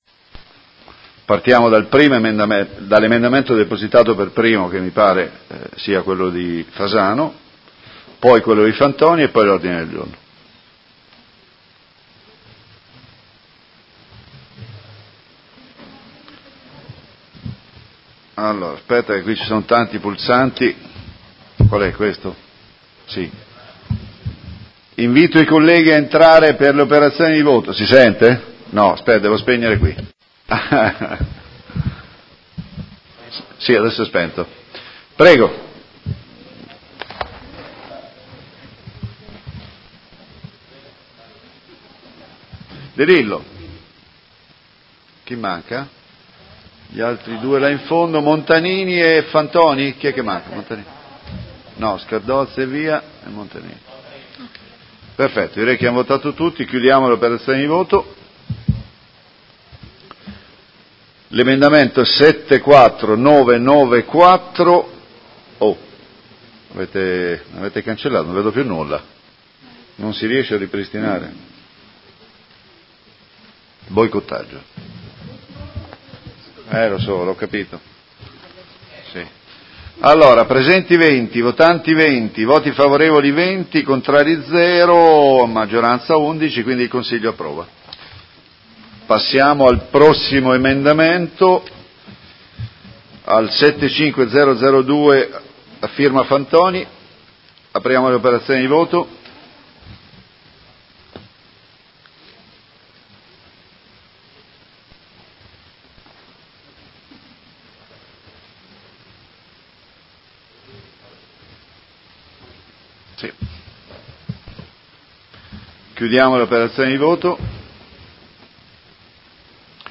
Seduta del 21/03/2019 Come Presidente di turno mette ai voti l'emendamento nr. 74994: approvato.